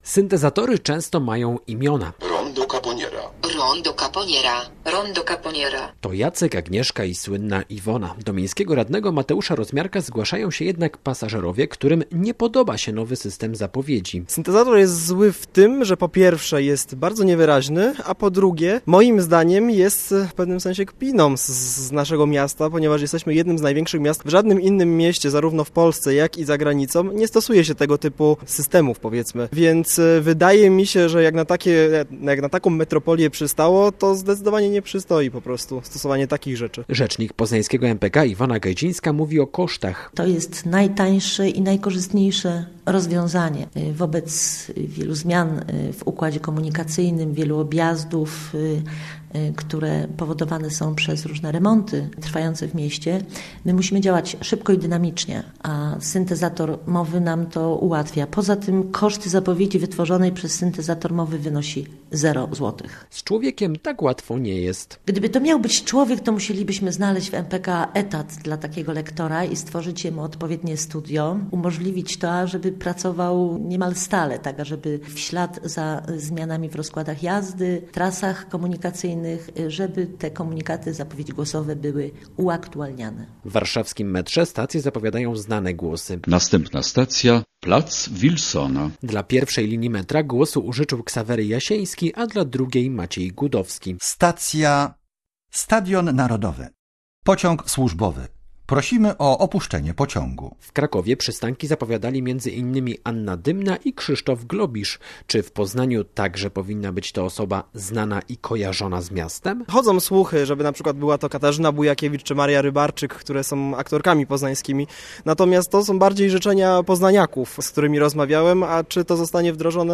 Syntezator mowy ma zapowiadać przystanki we wszystkich miejskich autobusach i tramwajach w Poznaniu.
1gp9ef93mt0kxek_jak-dziala-syntezator-mowy-w-tramwaju.mp3